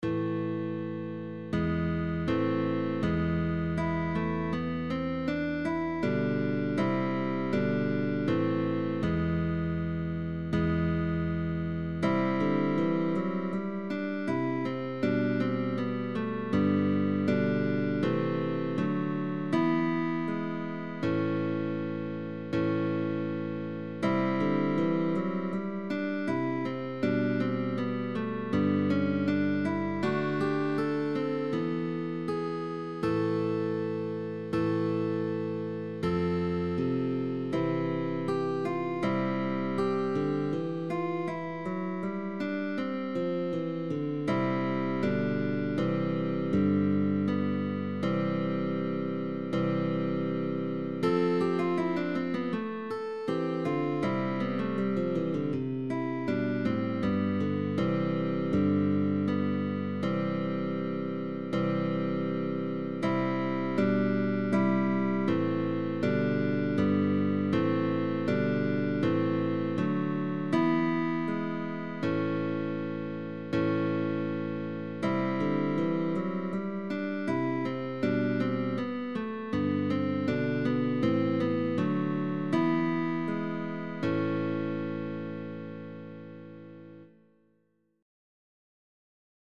MELODIC GUITAR. DUO: PUPIL AND TEACHER.
Early music